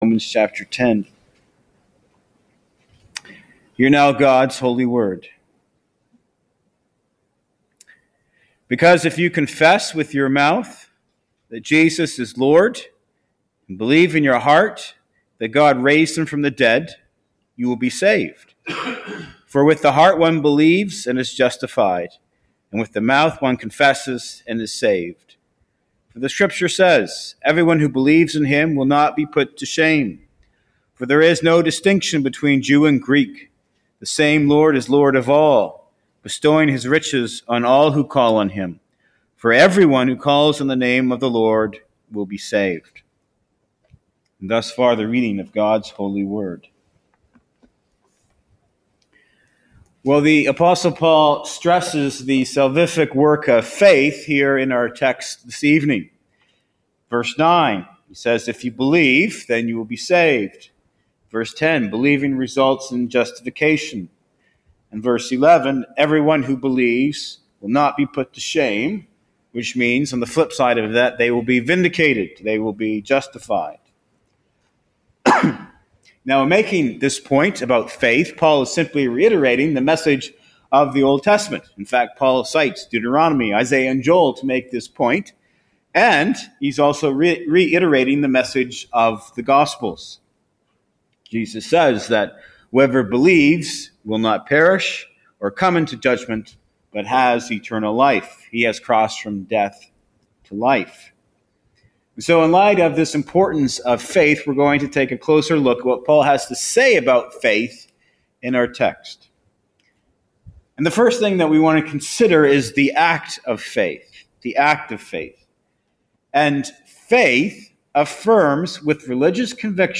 I had not previously heard faith explained so well and believe that many others may appreciate the content of this sermon.